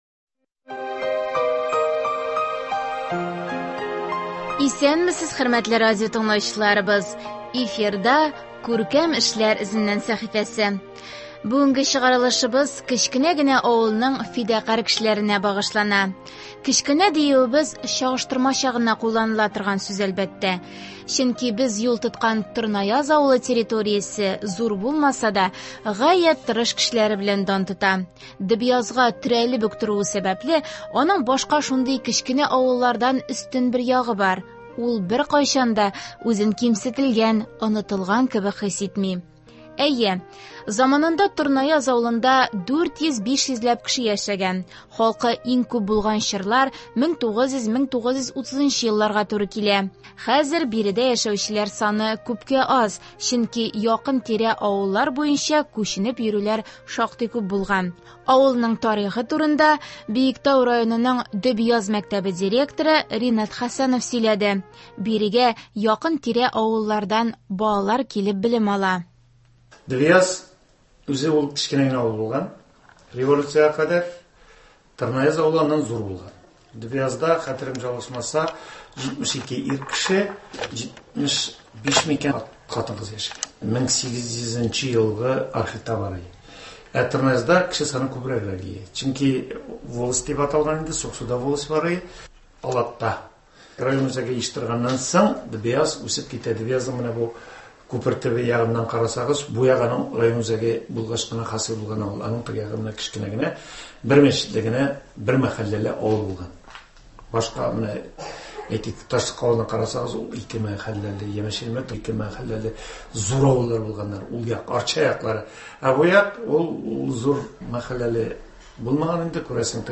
Биредә безне сыерларның мөгрәгән тавышлары гына түгел, гүләп торган машиналар да каршы алды.